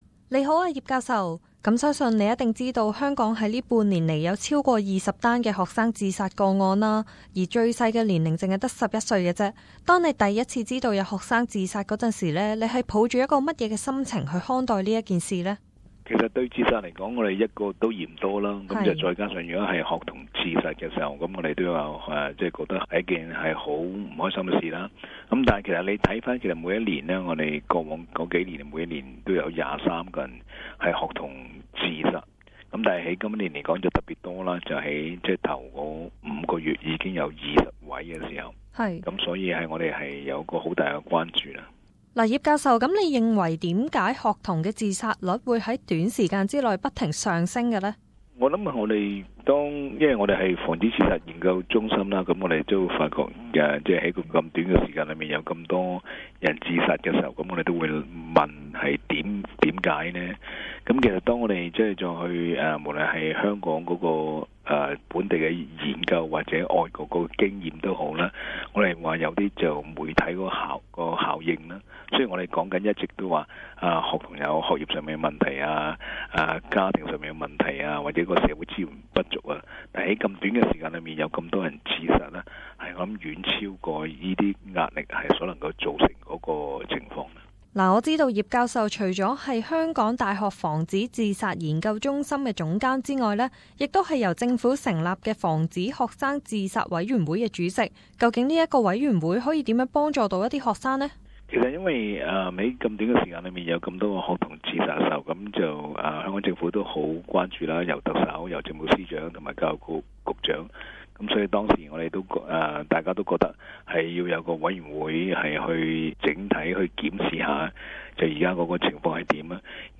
Internship student
SBS Cantonese